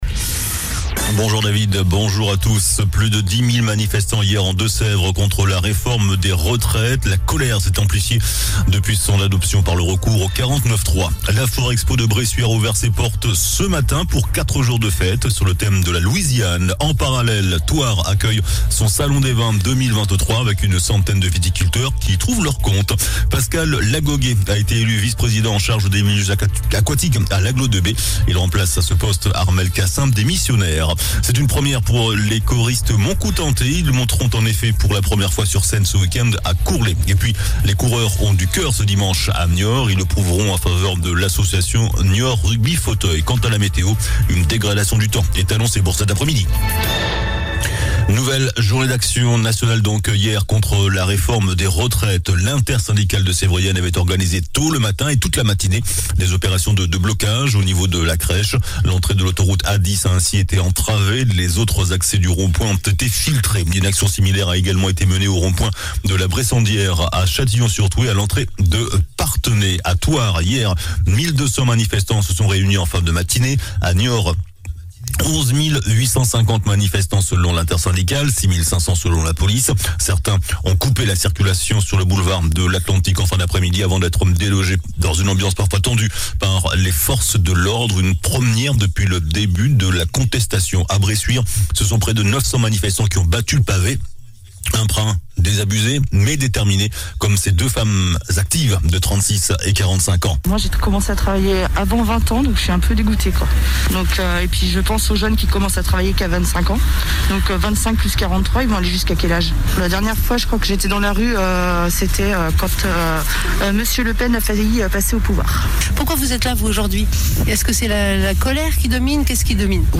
JOURNAL DU VENDREDI 24 MARS ( MIDI )